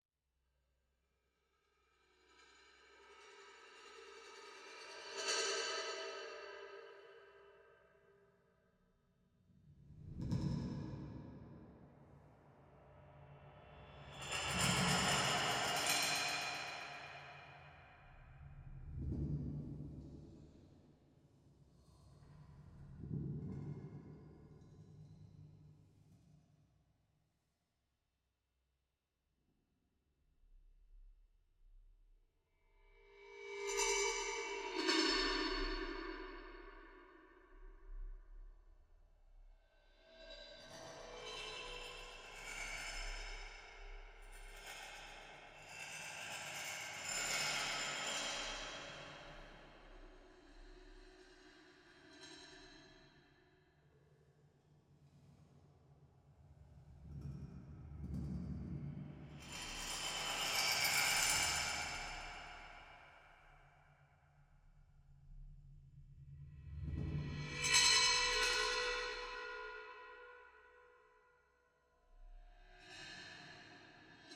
for soprano and piano
Orchestration: Für Sopran, Klavier und Elektronik